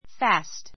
fǽst ふァ スト ｜ fɑ́ːst ふァ ー スト